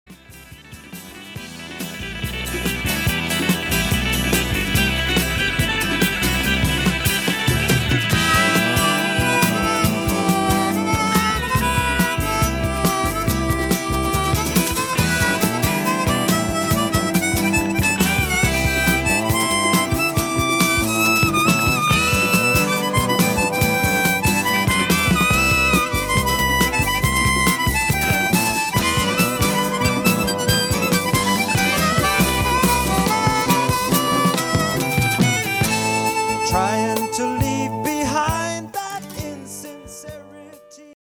vocals, harmonica
bass
drums
keyboards